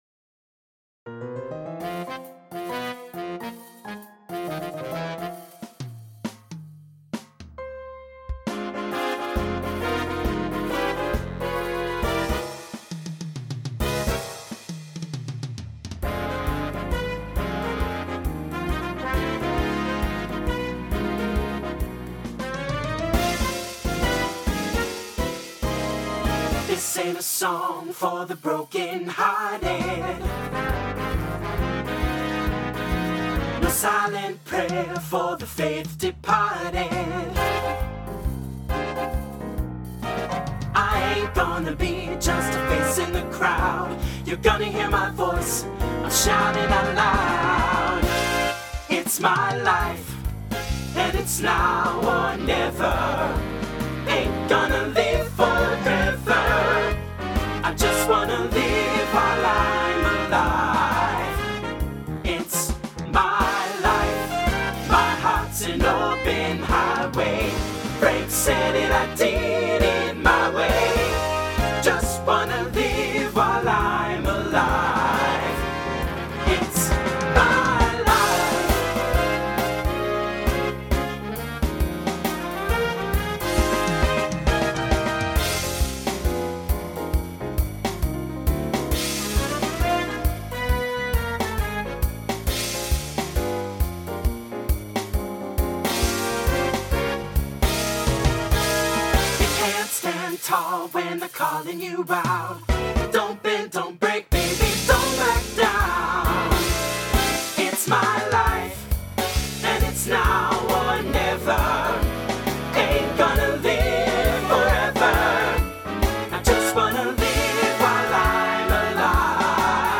SATB Instrumental combo
Swing/Jazz
Mid-tempo